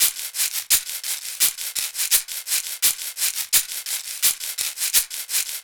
Index of /musicradar/analogue-circuit-samples/85bpm/Drums n Perc
AC_PercB_85-03.wav